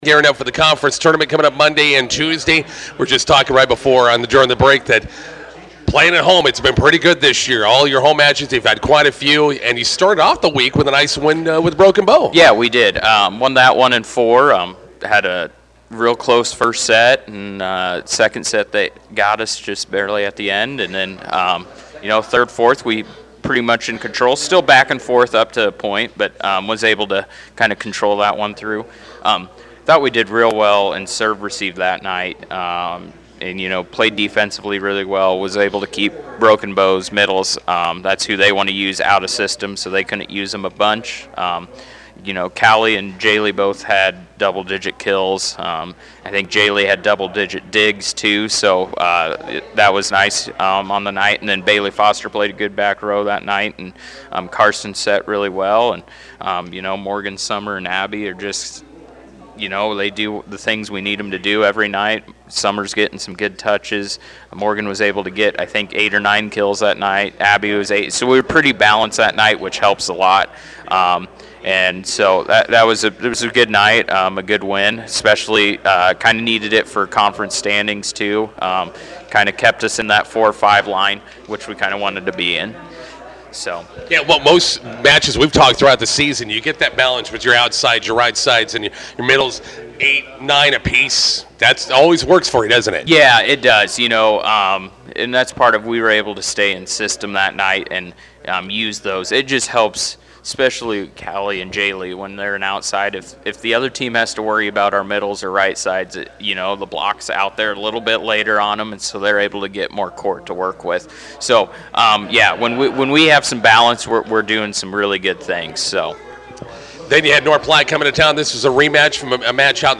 INTERVIEW: Bison volleyball heads to Cozad for Southwest Conference Tournament tonight vs. Gothenburg.